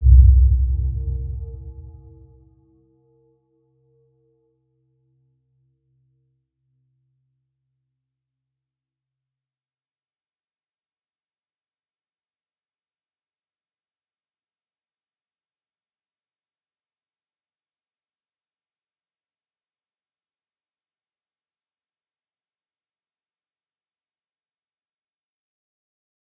Round-Bell-C2-p.wav